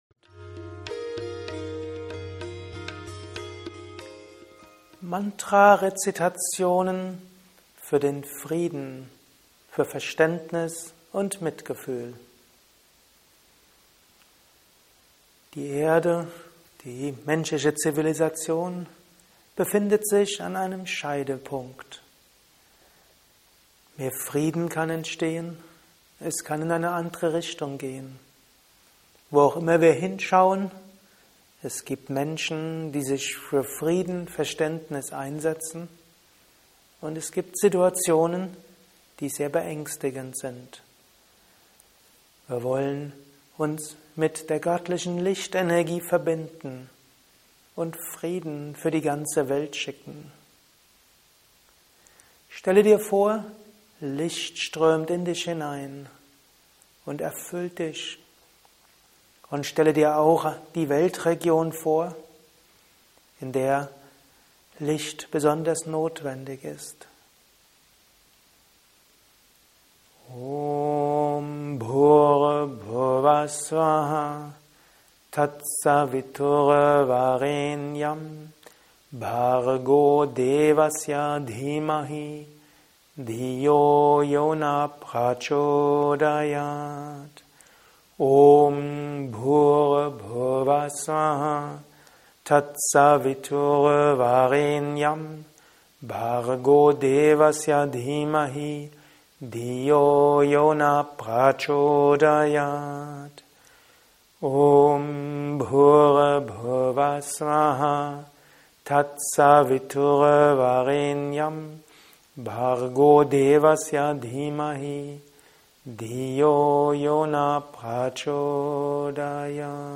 Mantra Rezitation für Frieden, Verständnis und Mitgefühl ~ Mantra-Meditation Anleitung Podcast
2016_Praxis-Video_MantraRezitation_Fuer-Frieden-Verstaendnis-und-Mitgefuehl.mp3